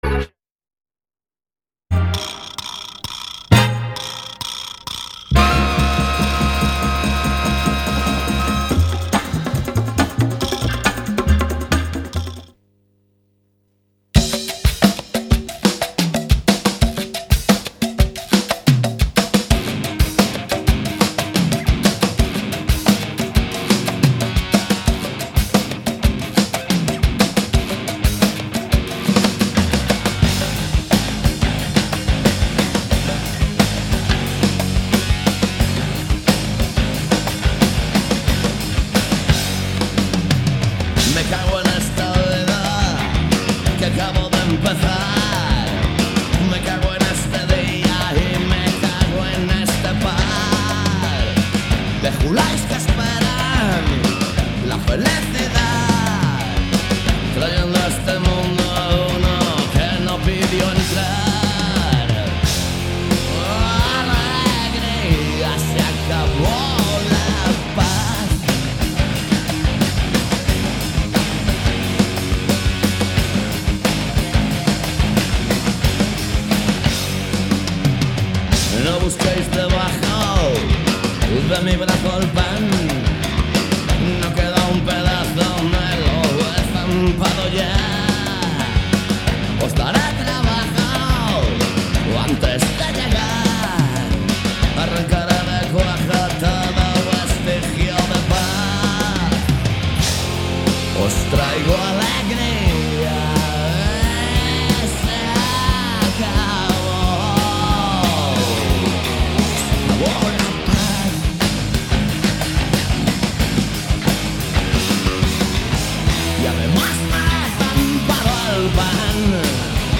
Todo iso misturado con boa música e un pouco de humor se o tema o permite. Cada martes ás 18 horas en directo.
Alegría comezou a emitirse en outubro de 2003 e dende entón non falla nas ondas de CUAC FM.